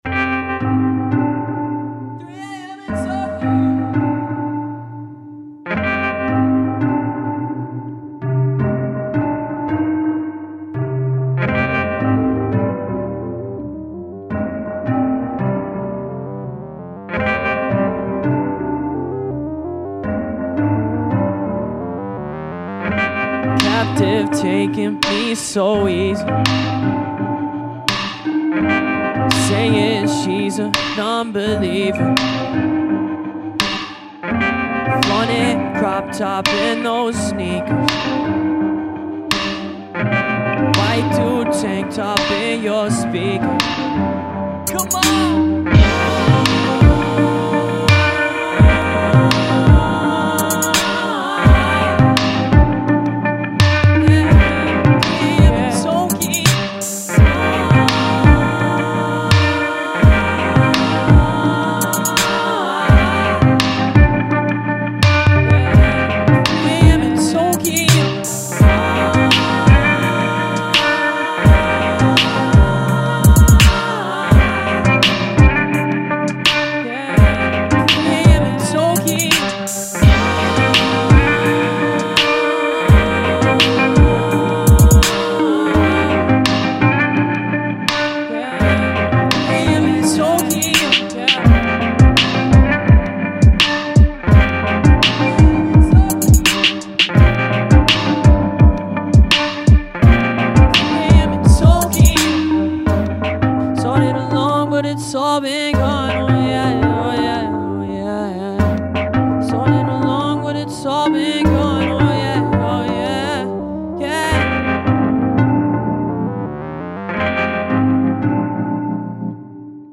Additional Synth